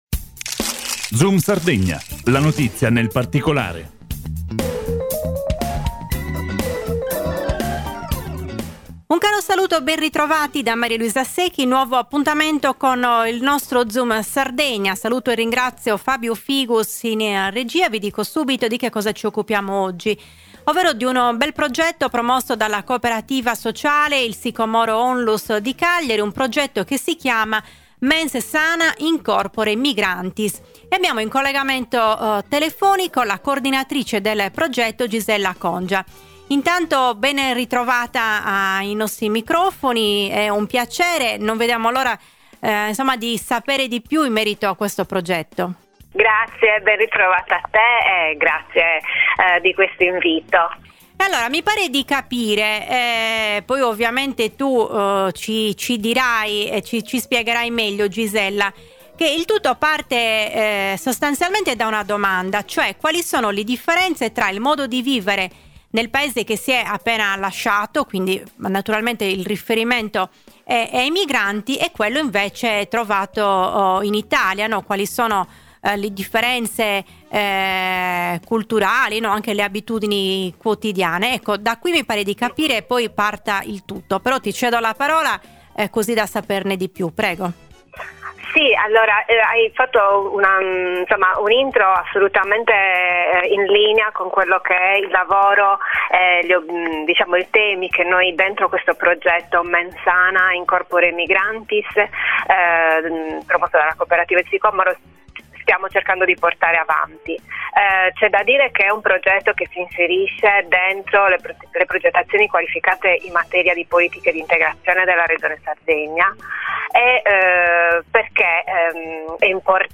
INTERVISTA-RADIO.mp3